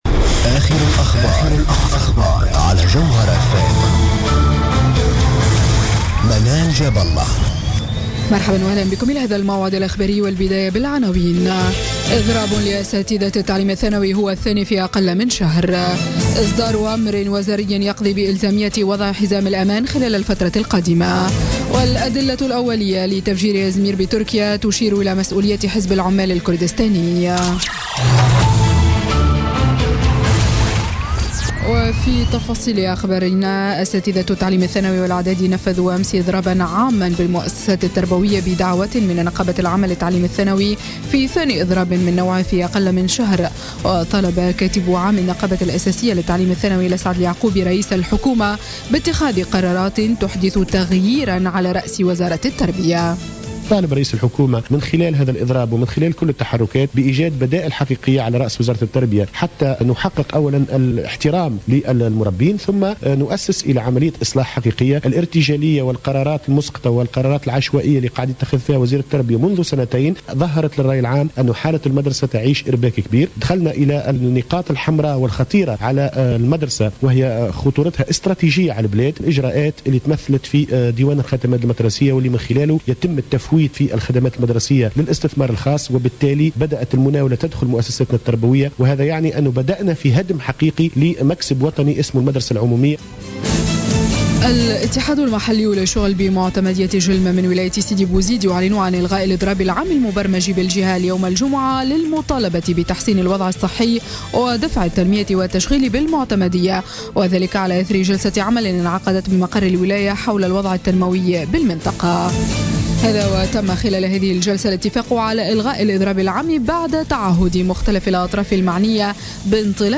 نشرة أخبار منتصف الليل ليوم الجمعة 6 جانفي 2017